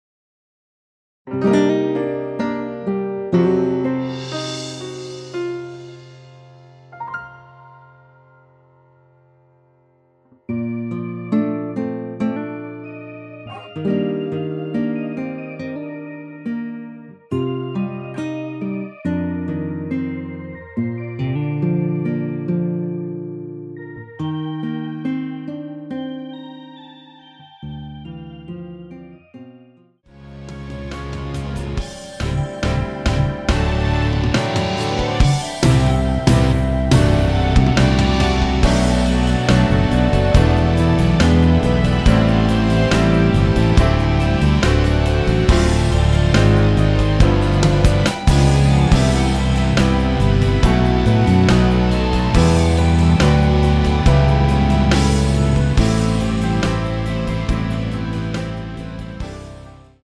원키에서(-2)내린 멜로디 포함된 MR입니다.
Bb
앞부분30초, 뒷부분30초씩 편집해서 올려 드리고 있습니다.